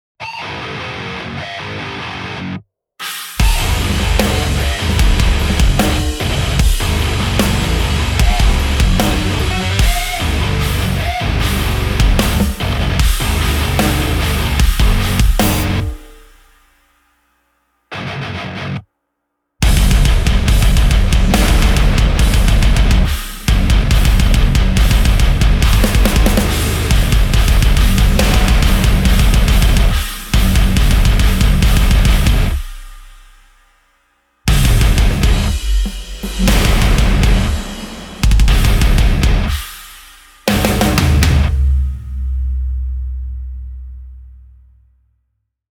• ダブル・キック・ドラムを真のステレオ・ポジショニングで収録
獰猛で、巨大なドラムをより強く叩く
ドラムがギターの壁を切り裂く。